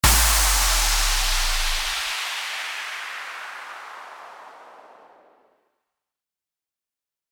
FX-1834-WHOOSH
FX-1834-WHOOSH.mp3